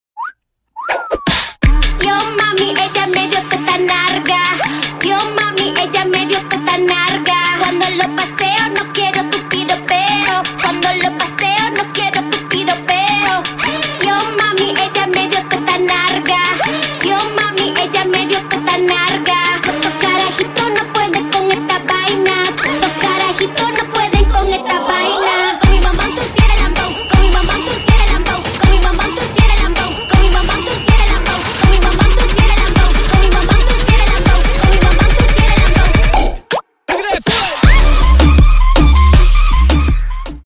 Латинские Рингтоны » # Громкие Рингтоны С Басами
Танцевальные Рингтоны